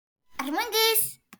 Play KID SAYING ARMOUNGUS! - SoundBoardGuy
Play, download and share KID SAYING ARMOUNGUS! original sound button!!!!
kid-saying-armoungus.mp3